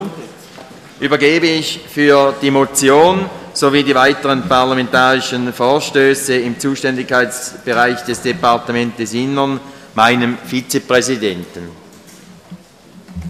(im Namen der SVP-Fraktion): Die Motion ist gutzuheissen.
Session des Kantonsrates vom 24. und 25. September 2012